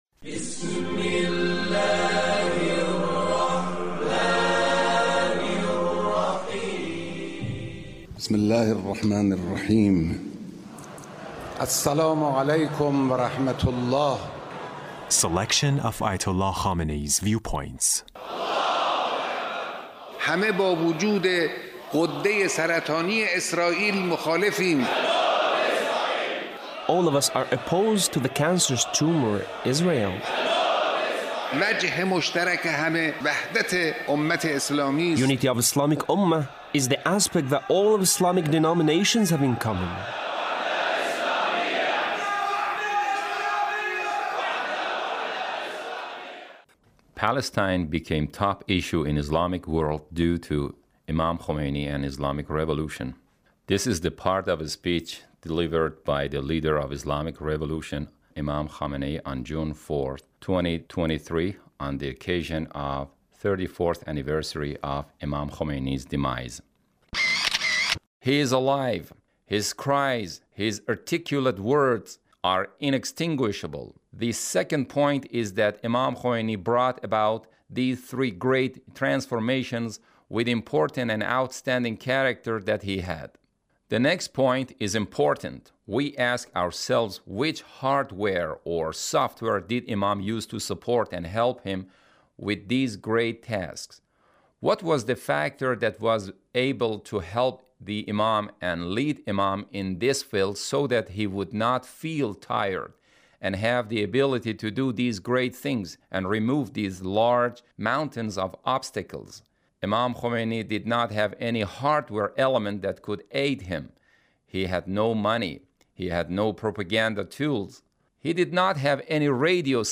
Leader's Speech on the occasion of the 34th anniversary of Imam Khomeini’s demise. 2023